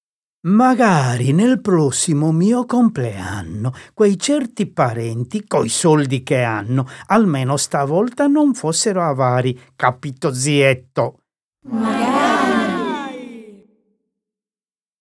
La sequenza completa e continua delle 23 quartine, coi loro cori a responsorio, è offerta in coda al libro con un QR-code.